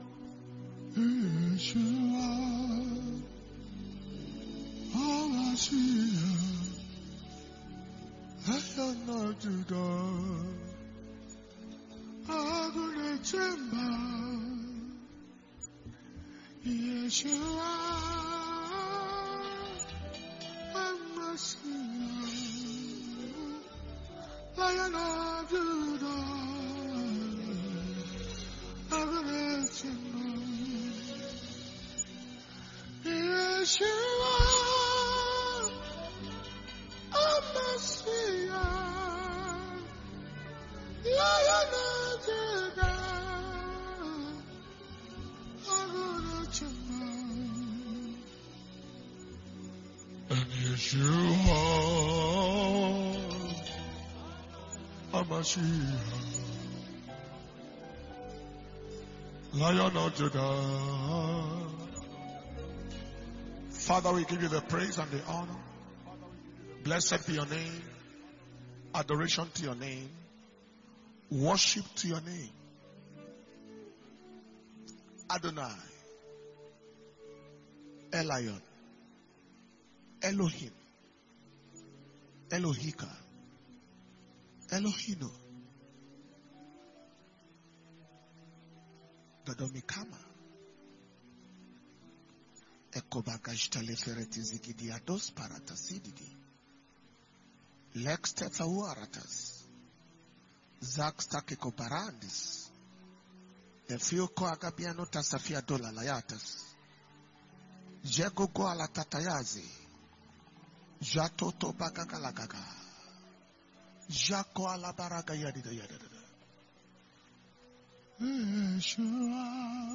Ignite Conference 2022.